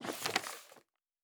Fantasy Interface Sounds